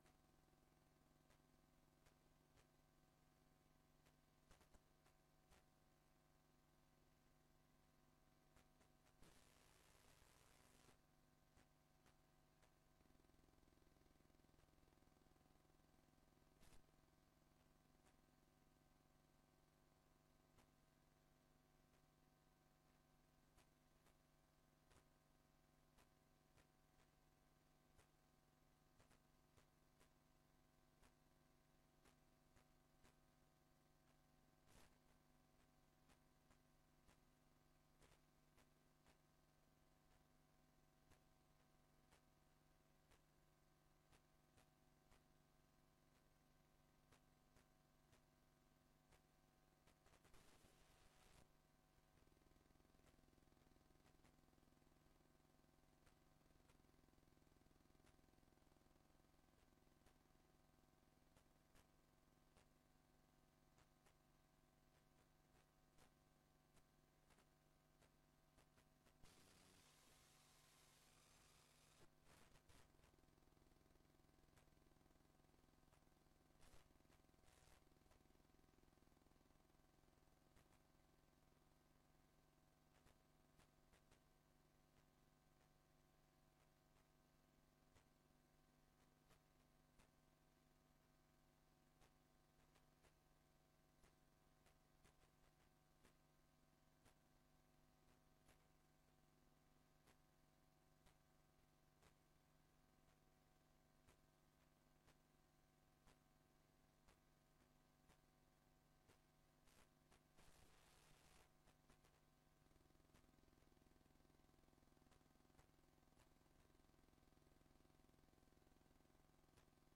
Locatie: Raadszaal